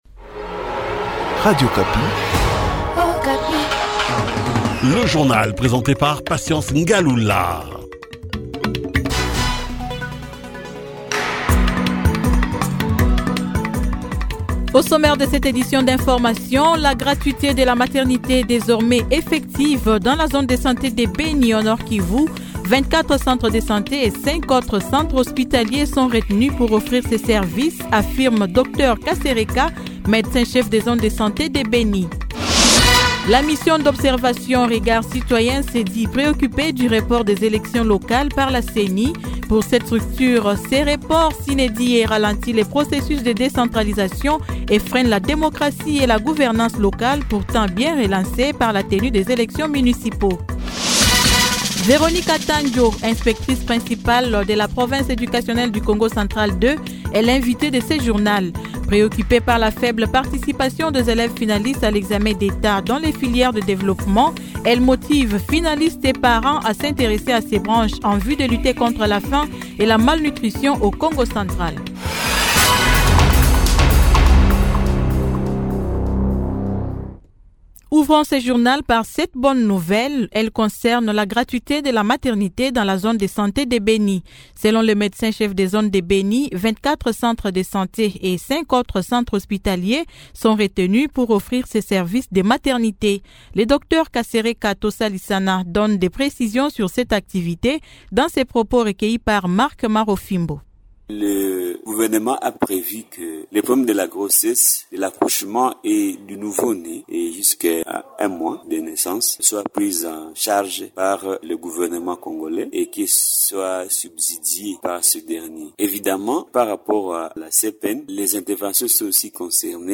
Journal matin 08H